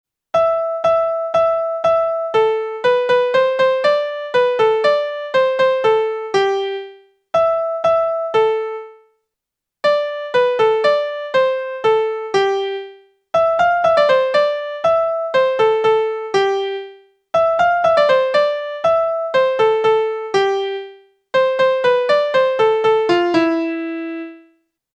The tune overall does not fit any of the ecclesiastical modes (also called tones), and we should not necessarily expect it to: some secular medieval music was clearly modal, but much more often secular music conformed to modes loosely or not at all.